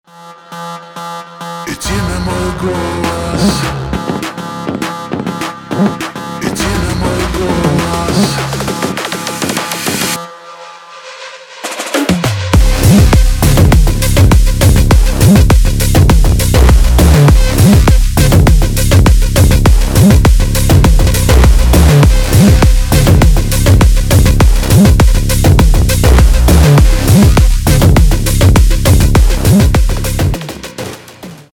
edm , electronic
клубные